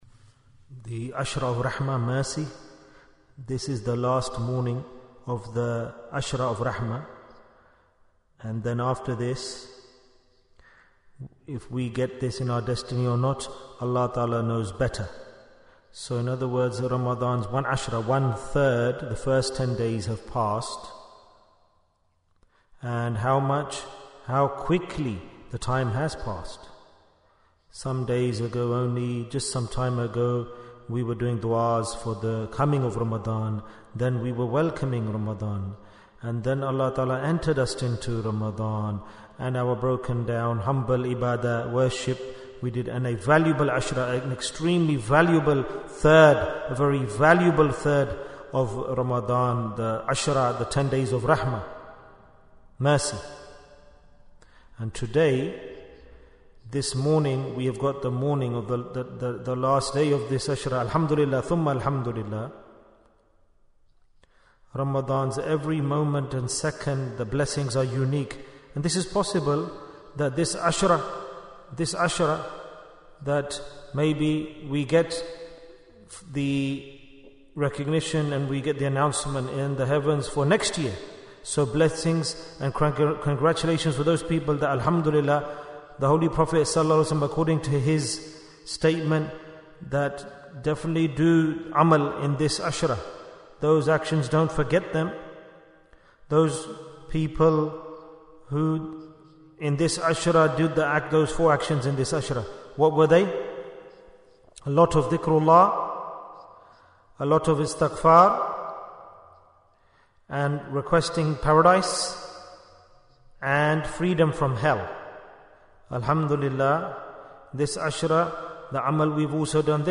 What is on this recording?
Bayan, 8 minutes